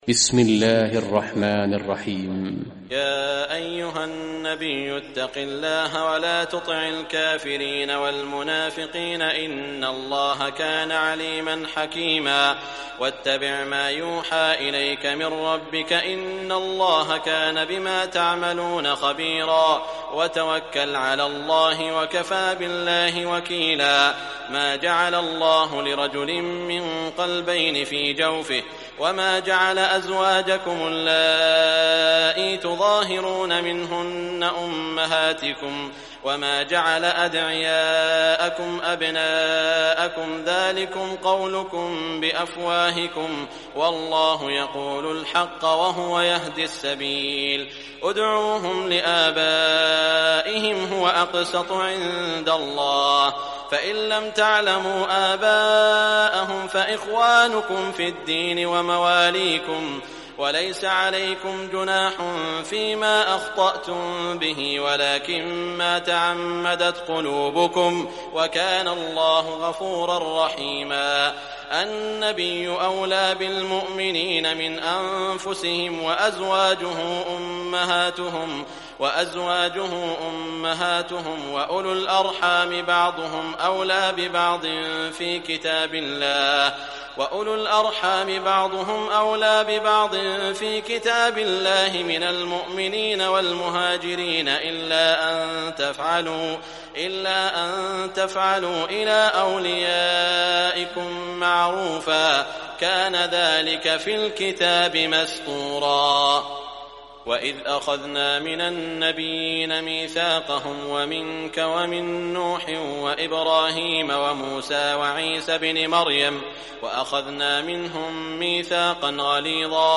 Surah Al-Ahzab Recitation by Sheikh Shuraim
Surah Al Ahzab, listen or play online mp3 tilawat / recitation in Arabic in the beautiful voice of Sheikh Saud Al Shuraim.